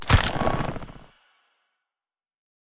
creaking_activate.ogg